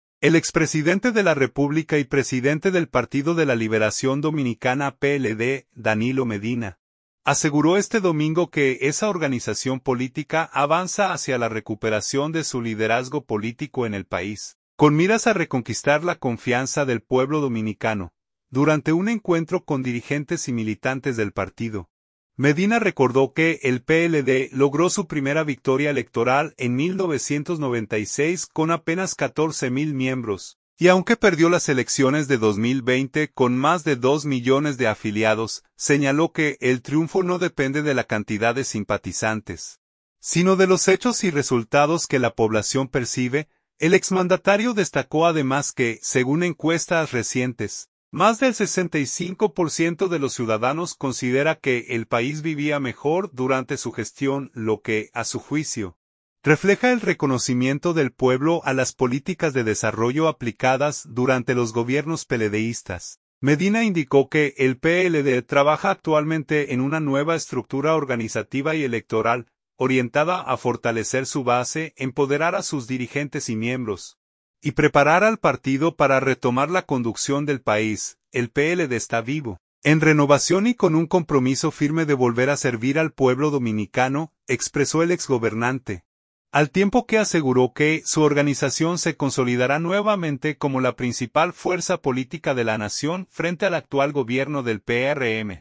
Durante un encuentro con dirigentes y militantes del partido, Medina recordó que el PLD logró su primera victoria electoral en 1996 con apenas 14 mil miembros, y aunque perdió las elecciones de 2020 con más de dos millones de afiliados, señaló que el triunfo no depende de la cantidad de simpatizantes, sino de los hechos y resultados que la población percibe.